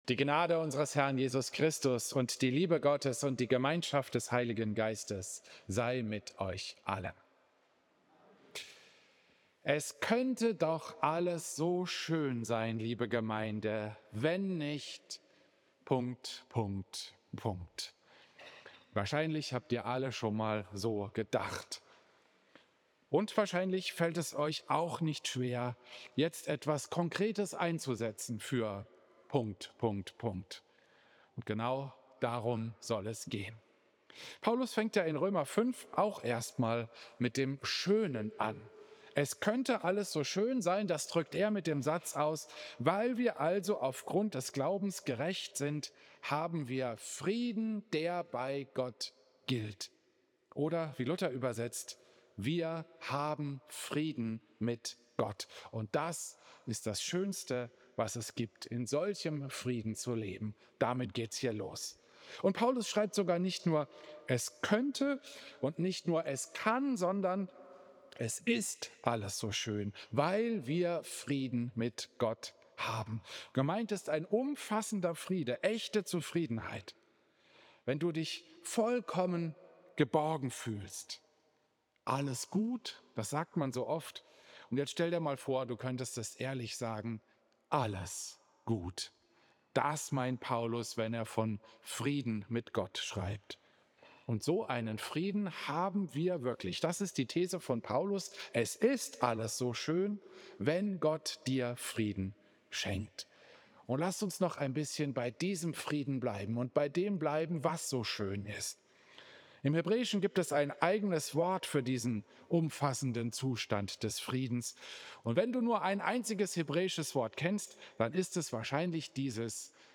Predigt
Klosterkirche Volkenroda, 1.